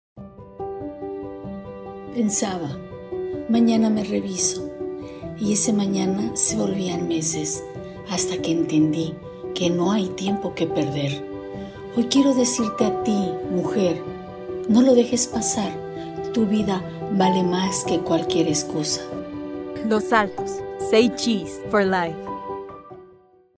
Historias reales de mujeres reales
Los nombres y voces utilizados son ficticios, con el objetivo de transmitir mensajes universales de prevención y esperanza.
testimonial-2-v2.mp3